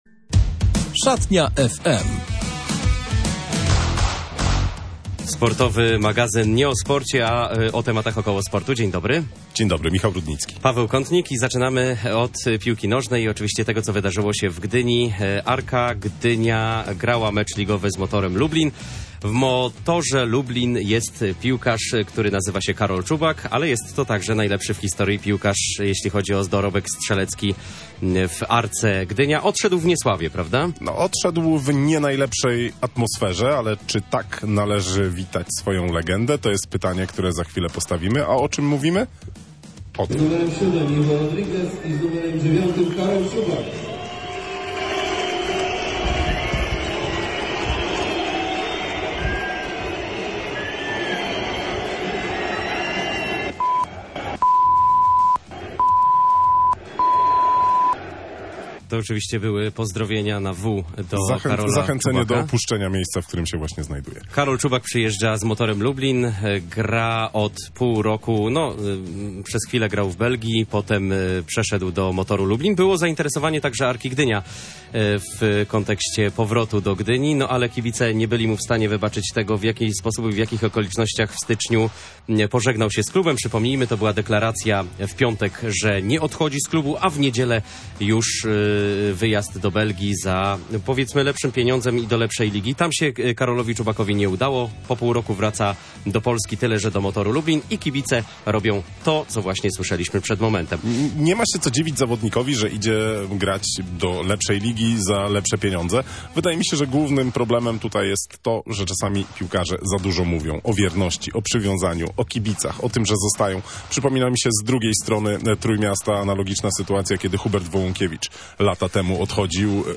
I taki człowiek był właśnie gościem „Szatni FM”.